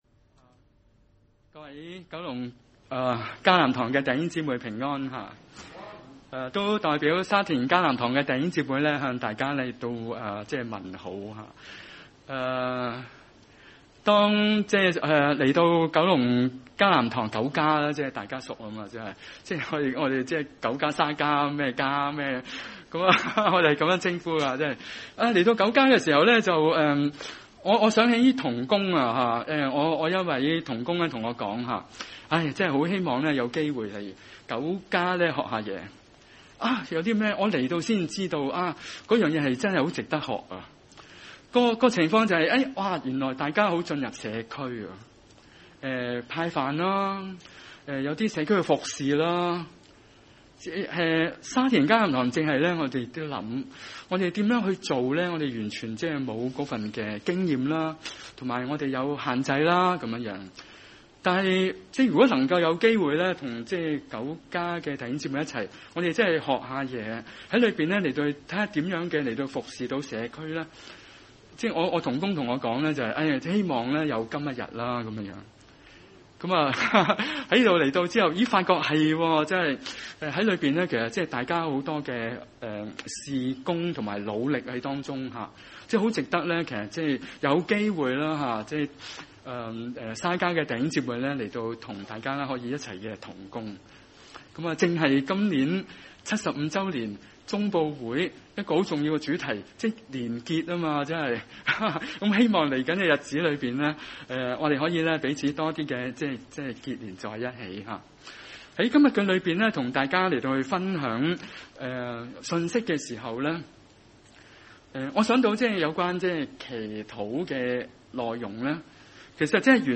31 崇拜類別: 主日午堂崇拜 27 雅各阿、你為何說、我的道路向耶和華隱藏。